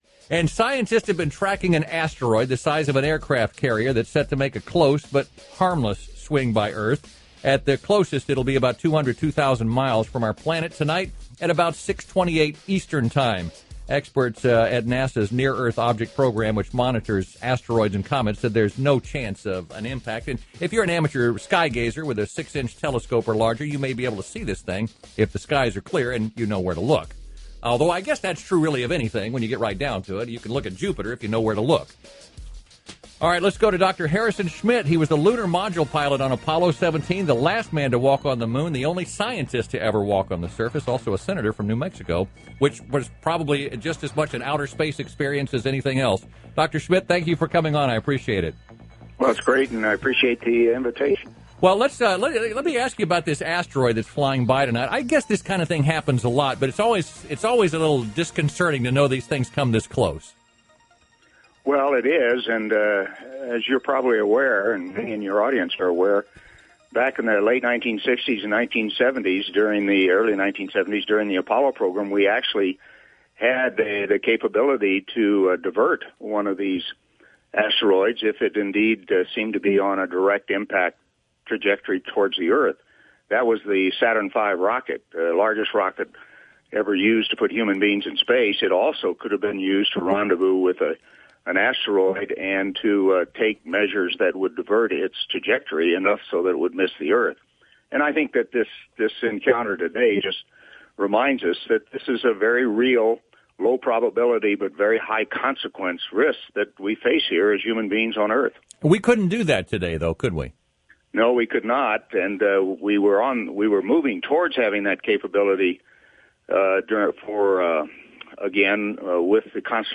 Video/Radio Interviews
Radio